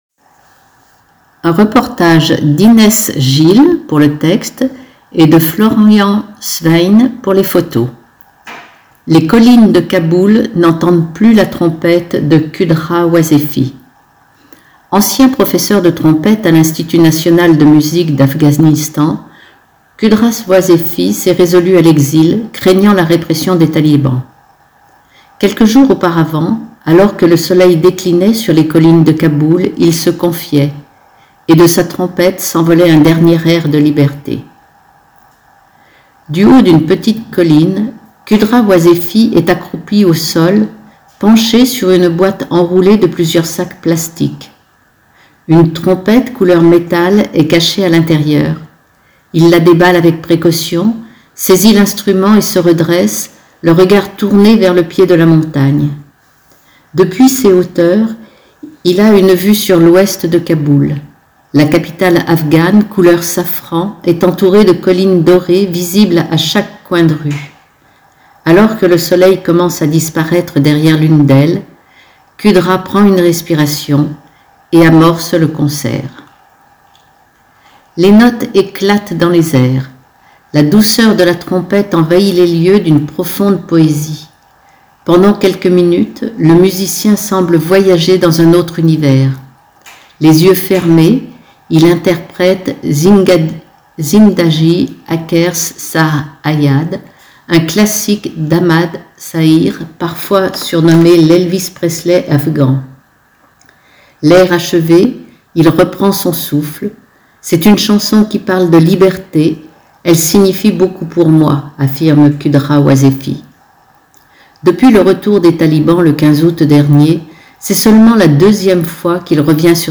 Dossier "Visages de la paix" Pour écouter ce reportage, cliquer sur le fichier audio au bas de l'article Du haut d’une petite colline